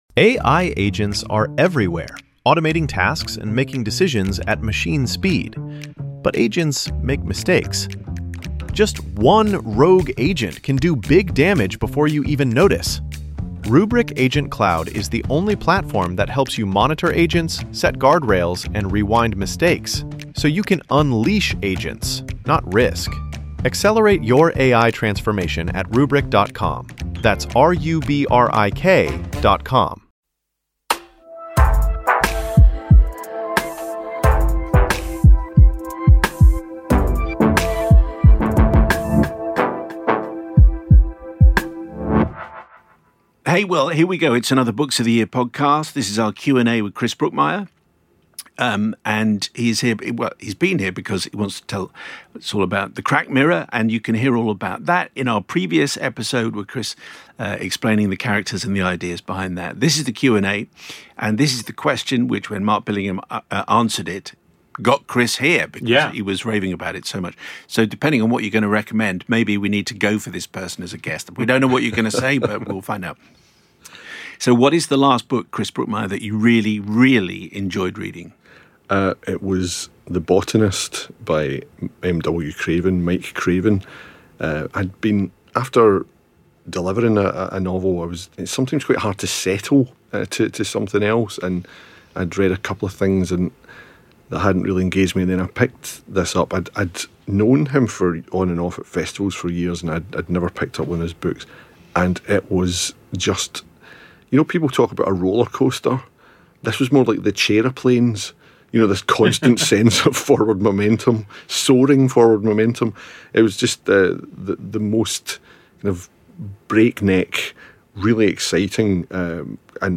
Chris Bookmyre Q&A